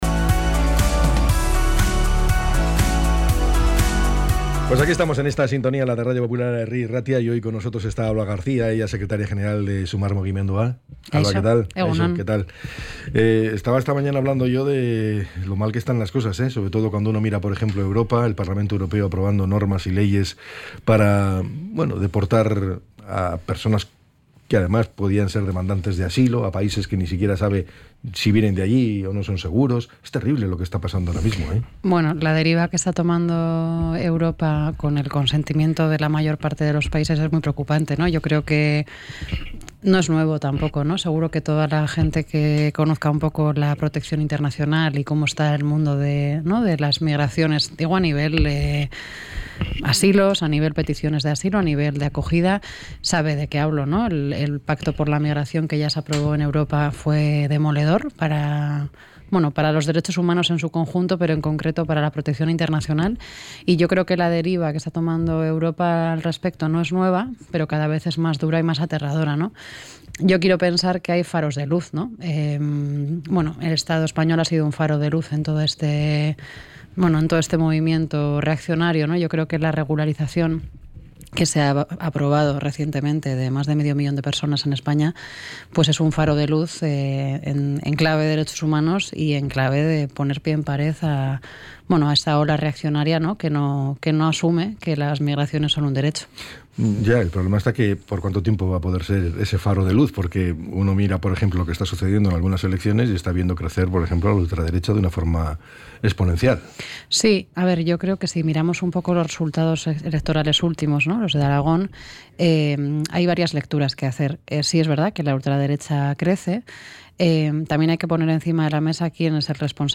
ENTREV.-ALBA-GARCIA.mp3